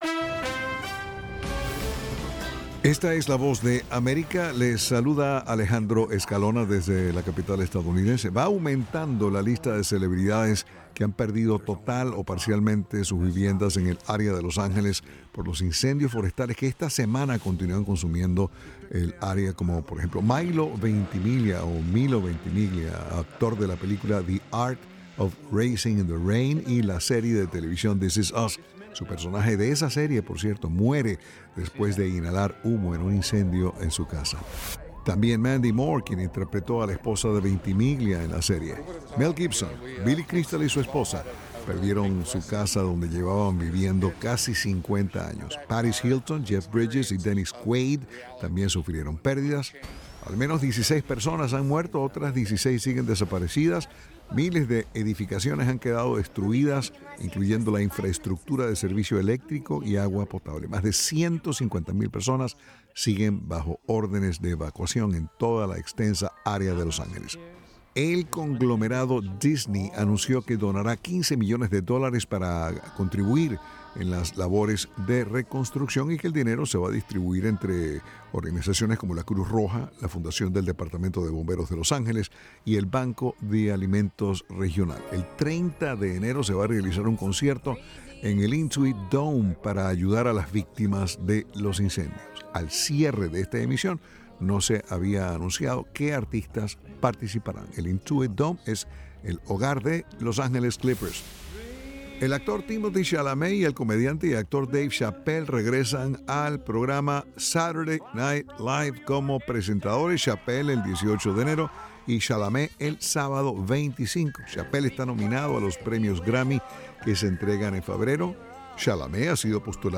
con las noticias del espectáculo por la Voz de América.